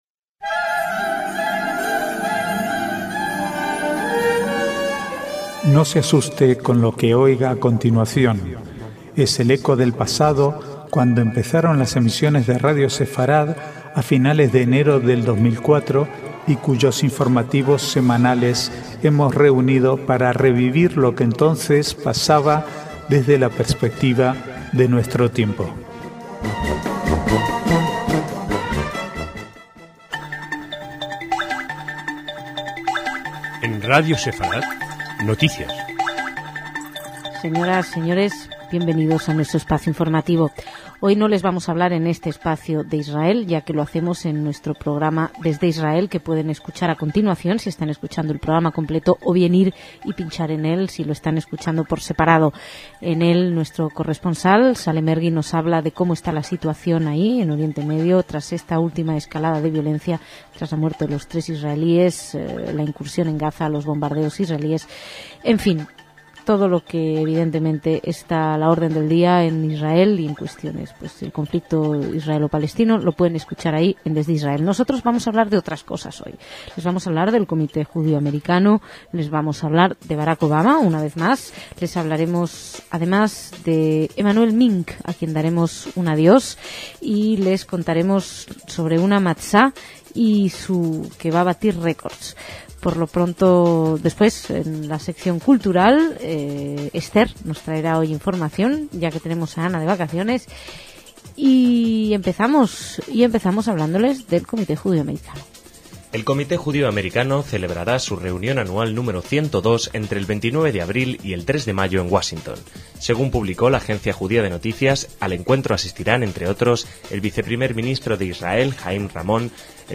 Archivo de noticias del 18 al 23/4/2008